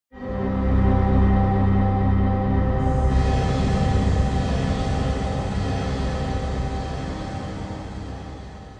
Windows Xp Error Music Ringtone Enhanced Sound Effect Download: Instant Soundboard Button